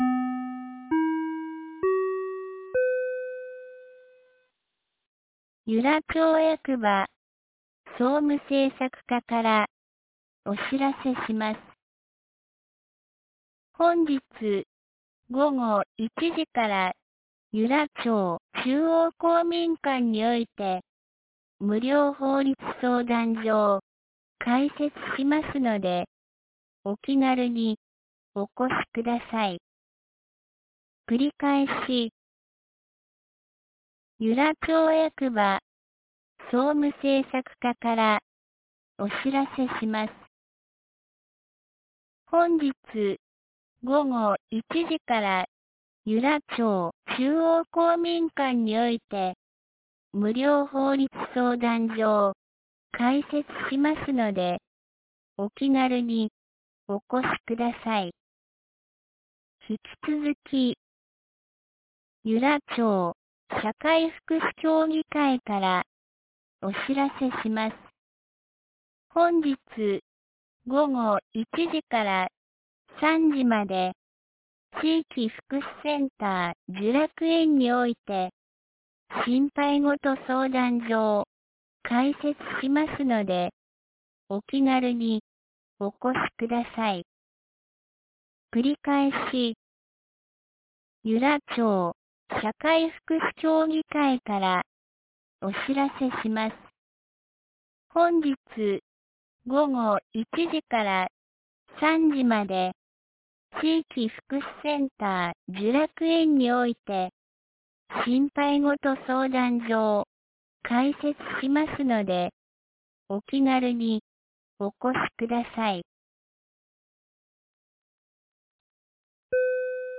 2019年10月10日 12時22分に、由良町から全地区へ放送がありました。